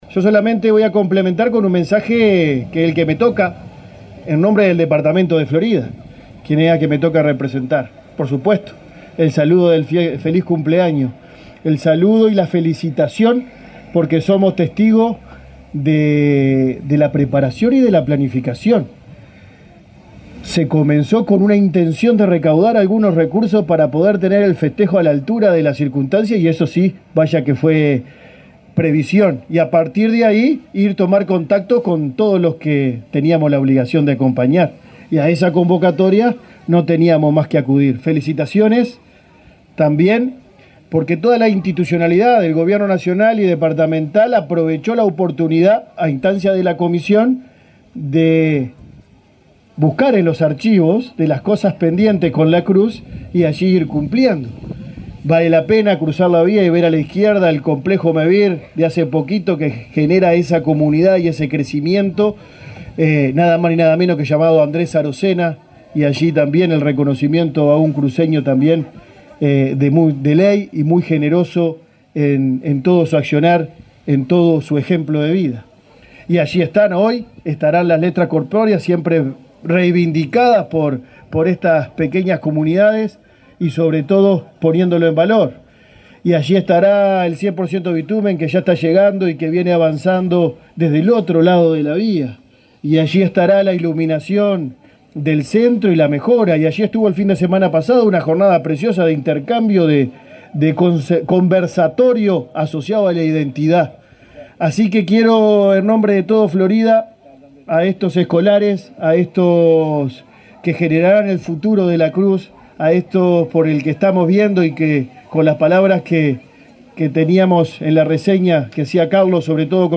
Palabras del intendente de Florida, Guillermo López
Palabras del intendente de Florida, Guillermo López 24/10/2024 Compartir Facebook X Copiar enlace WhatsApp LinkedIn El intendente de Florida, Guillermo López, se expresó, este jueves 24, durante los festejos por el aniversario de la localidad de La Cruz, en ese departamento.